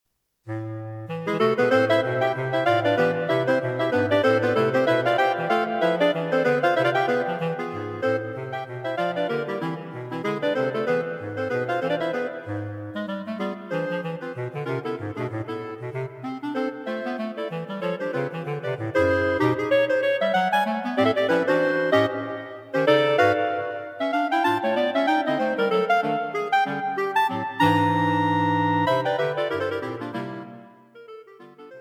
Clarinet Quartet
this Modern Jazz piece has a hint of the exotic about it.